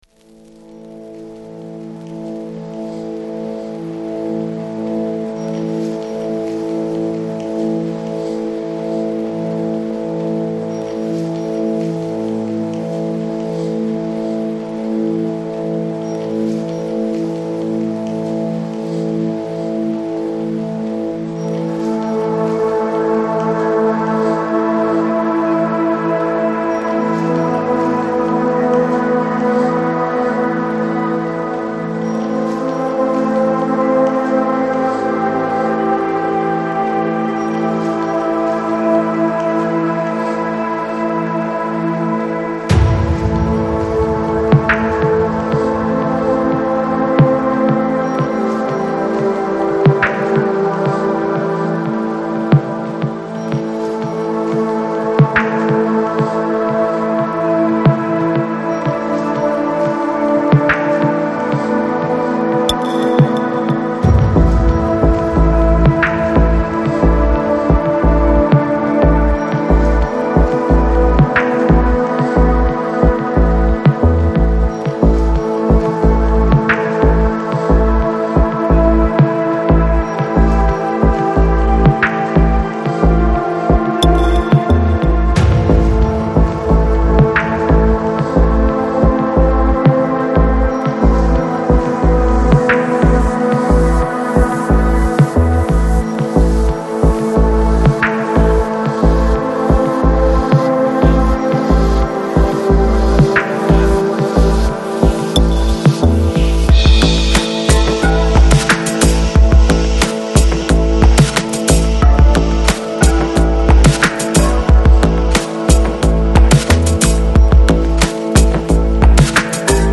Жанр: Electronic, Chill Out, Lounge